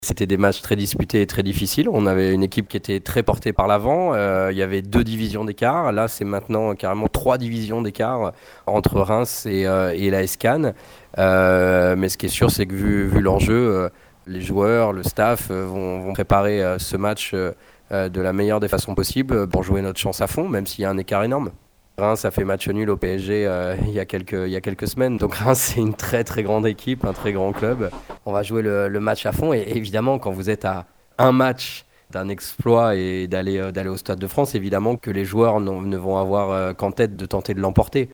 Interrogé par l’agence de presse SmartRadio